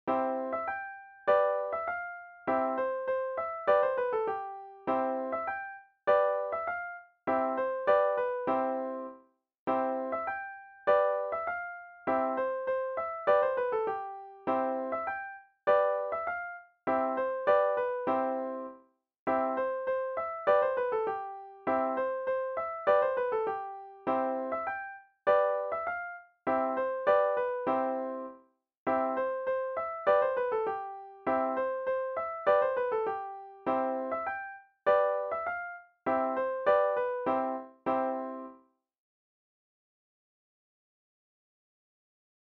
Deense volksmuziek